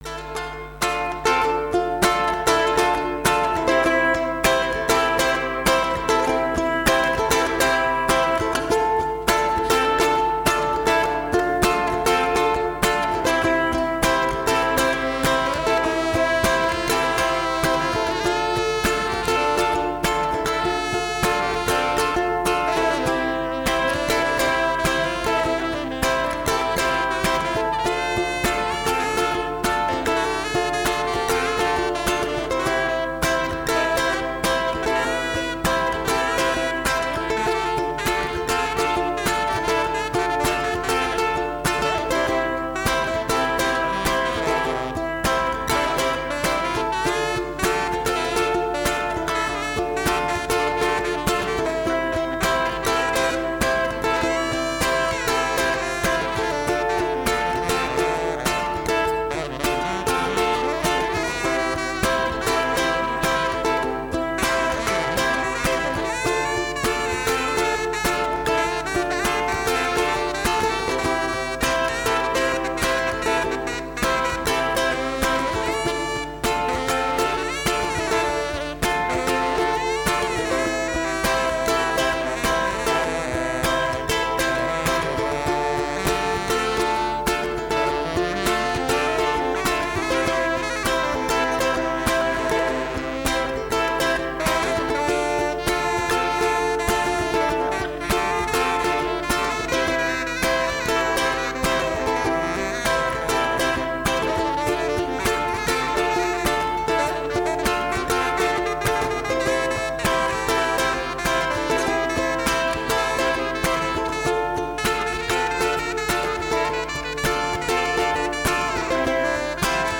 Armonica, chitarra e voce!